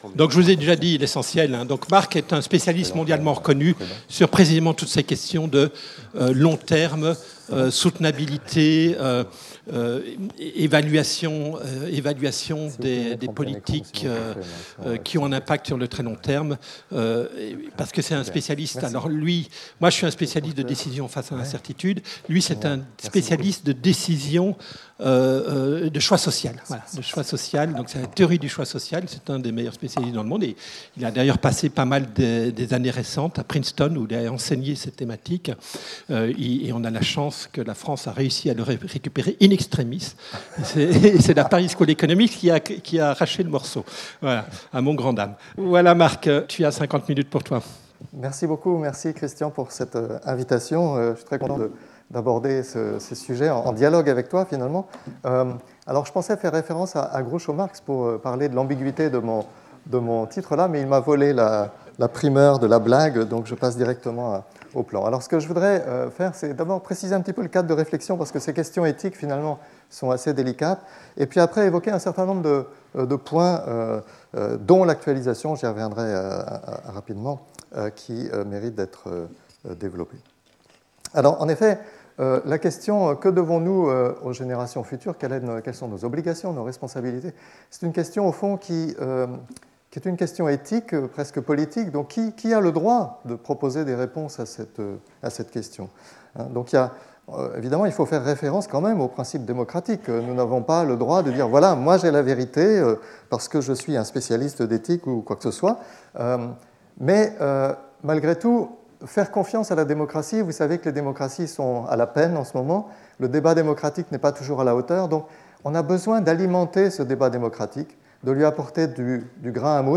Séminaire